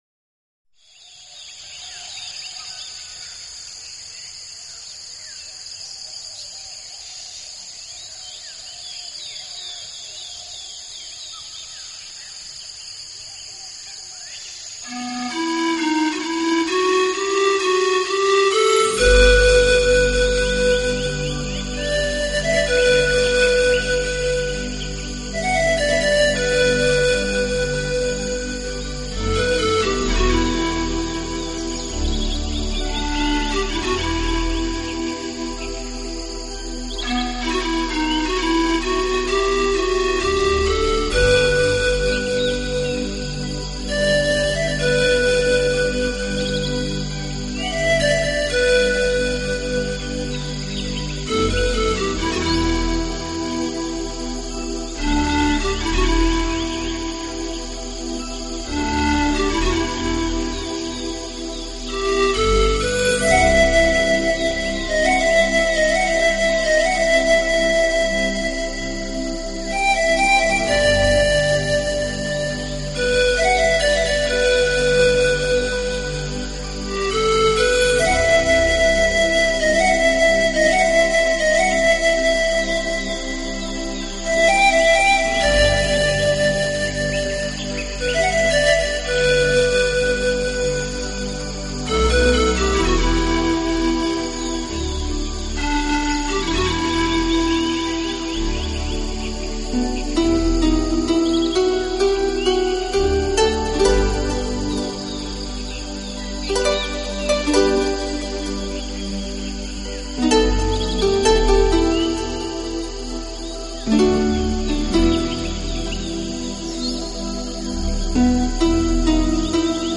听过他们音乐的读者大致可以了解，该团的音乐风格通常是以电子
器配置，使每首曲子都呈现出清新的自然气息。